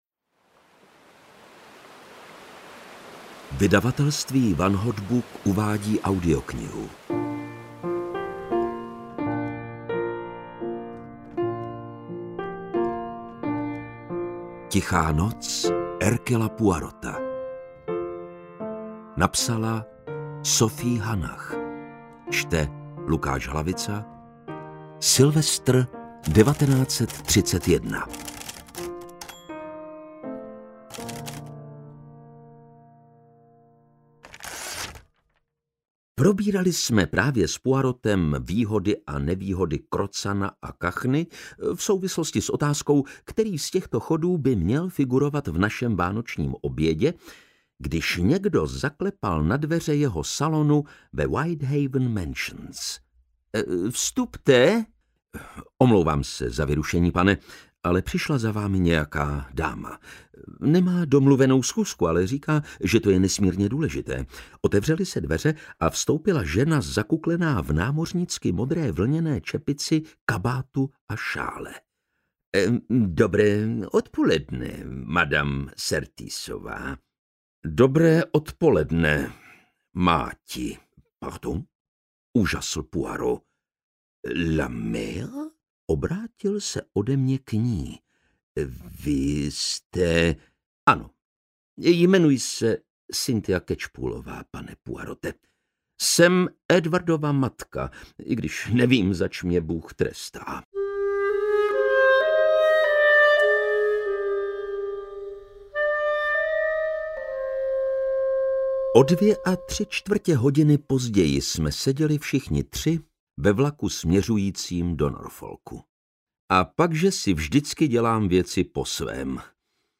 Ukázka z knihy
ticha-noc-hercula-poirota-audiokniha